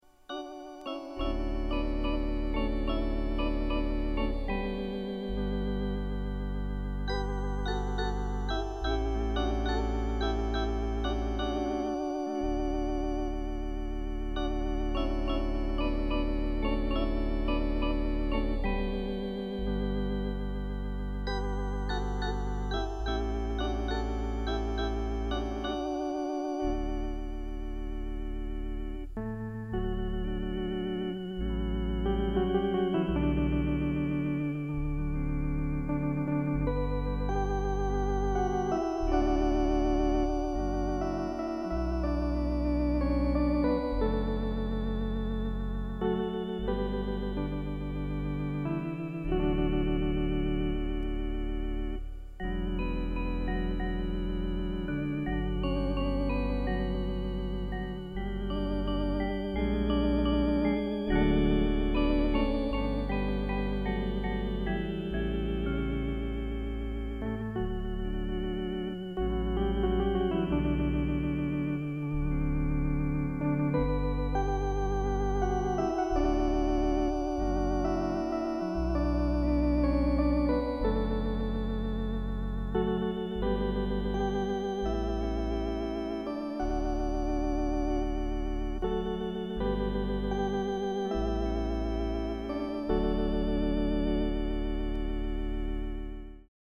Post tonewheel – Concorde
Ebb Tide — Once upon a Time in the West (x2) — Raindrops keep falling on my head — Mozart: petite musique de nuit. Practice tunes from a slow learner, 1980.